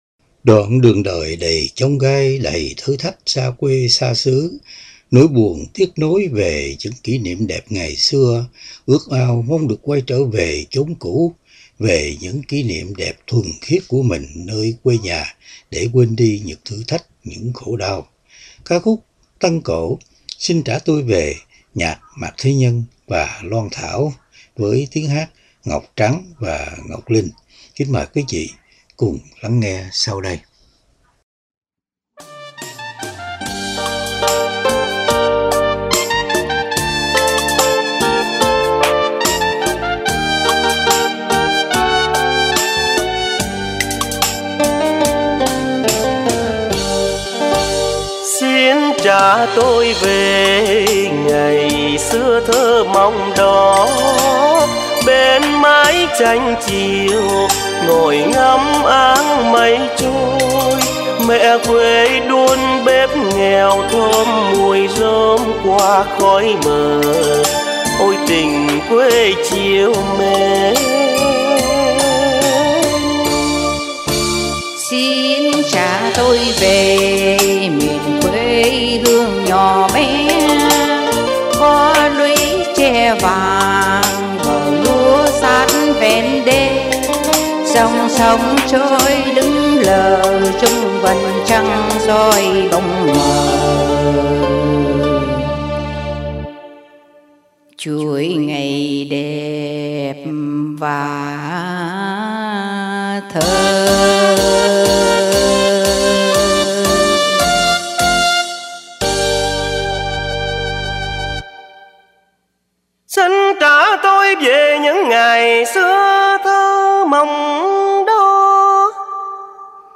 Tân Cổ
Song ca